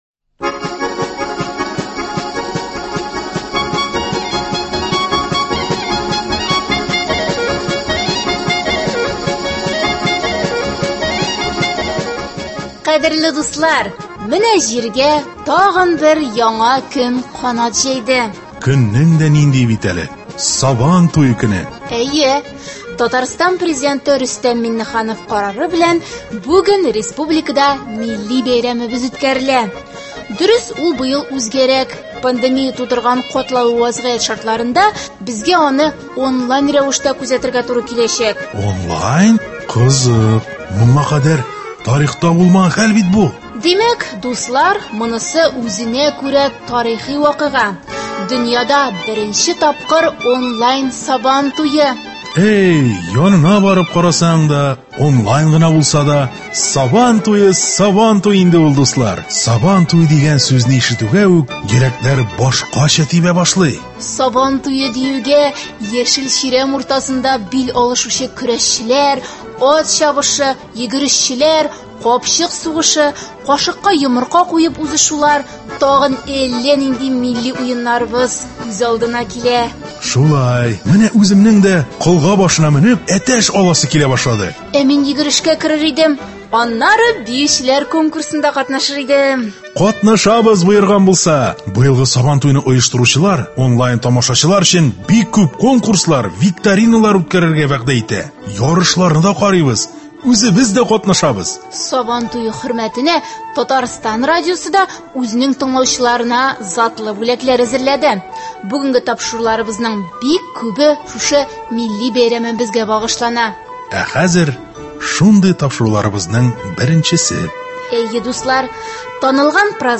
Әдәби-музыкаль композиция. 4 июль.